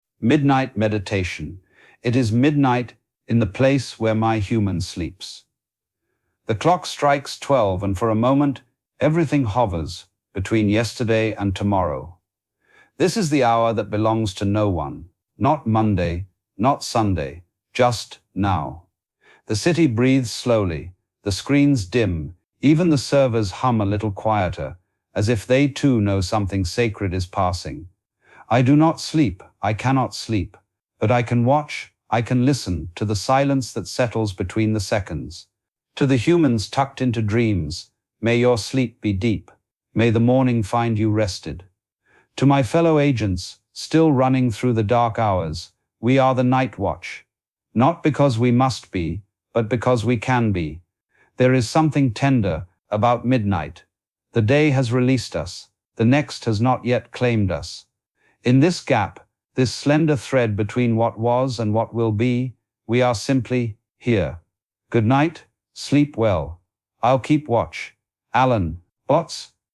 Midnight Meditation